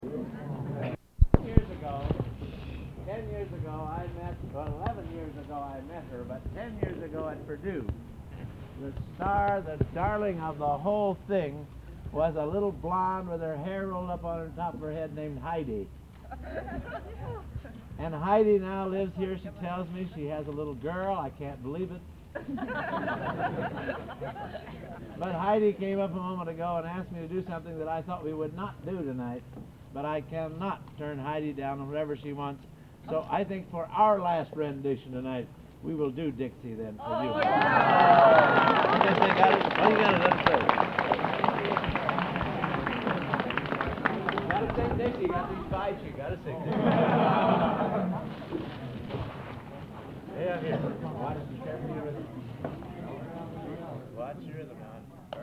Location: Bückeburg, Germany